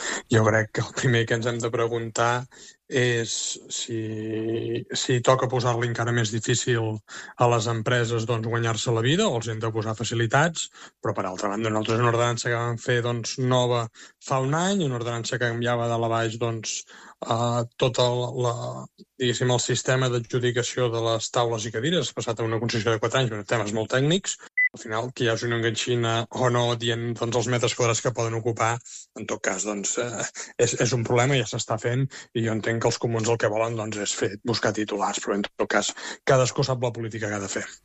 En una entrevista a l’espai Ona Maresme, Buch ha defensat la gestió del govern i ha afirmat que el debat no hauria de centrar-se només en la norma, sinó en el context econòmic i empresarial del municipi.